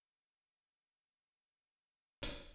Trem Trance Drums Intro.wav